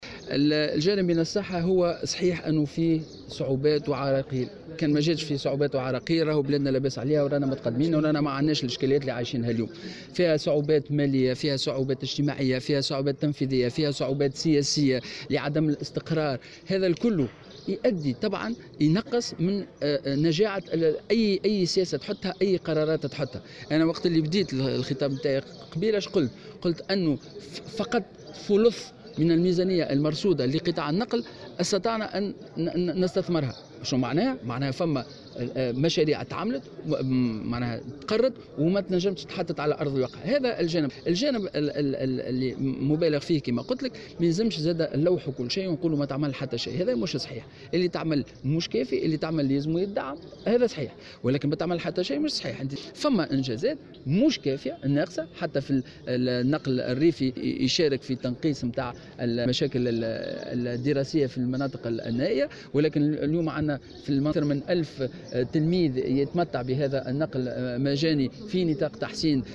اشرف صباح اليوم 1 ماي2019 بمقر الولاية وزير التعليم العالي سليم خلبوس على جلسة الحوار الجهوي حول قطاع النقل تأتي الجلسة في إطار التحضير للحوار الوطني حول نفس القطاع الذي سيشرف عليه رئيس الحكومة يوسف الشاهد.